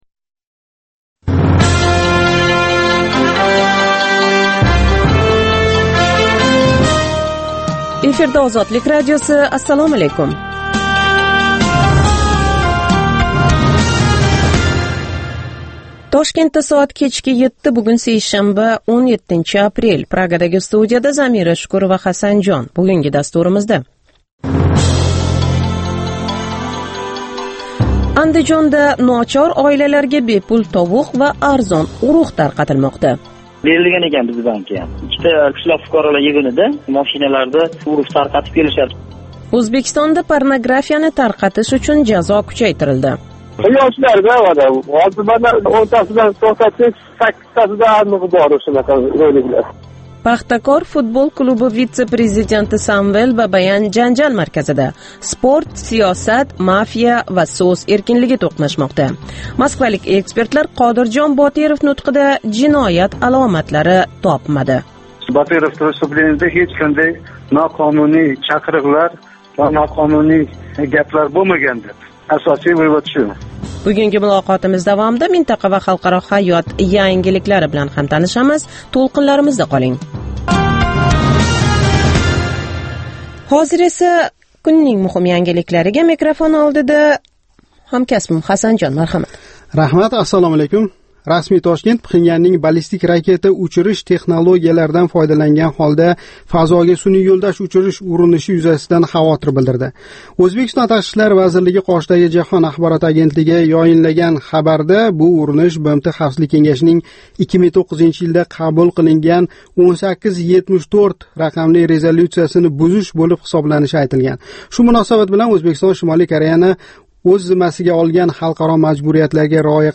Жонли эфирдаги кечки дастуримизда сўнгги хабарлар, Ўзбекистон, Марказий Осиë ва халқаро майдонда кечаëтган долзарб жараëнларга доир тафсилот ва таҳлиллар билан таниша оласиз.